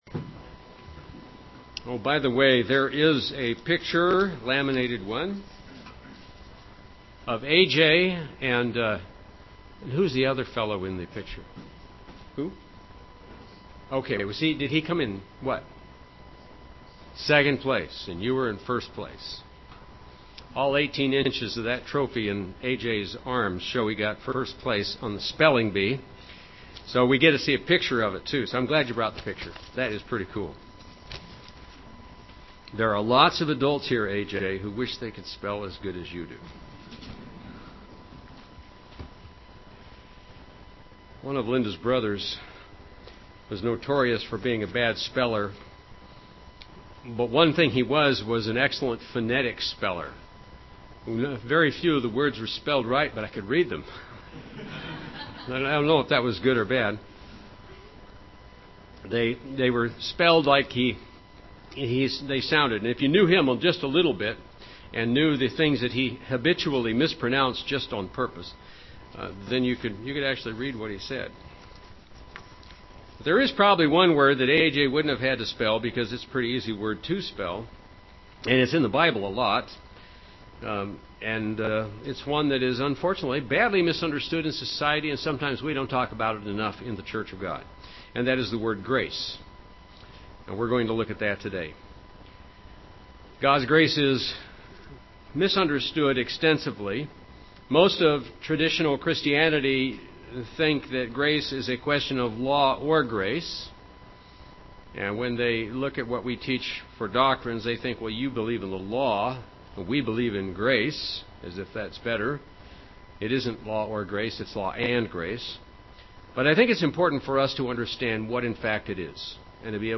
Print God's grace and the sacrifice of Jesus Christ. The Grace in Which We Stand, Part 1 UCG Sermon Studying the bible?